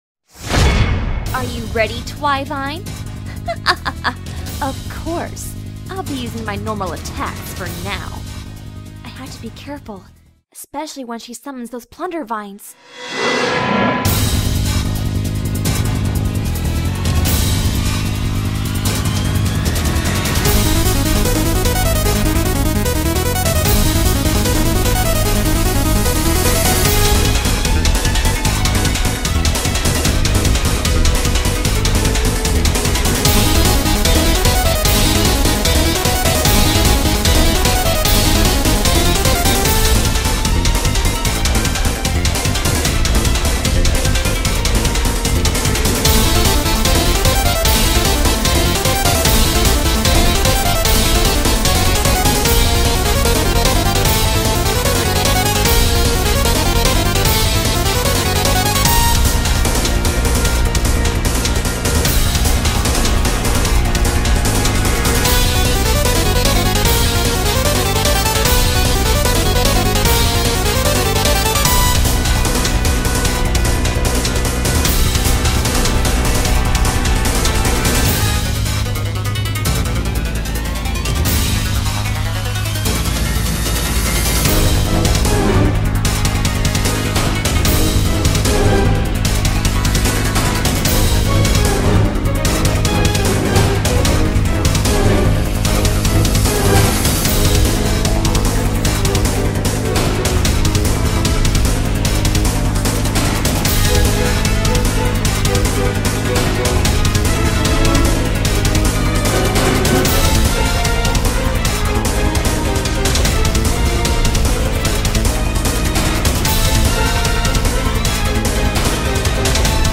genre:soundtrack